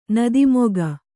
♪ nadi moga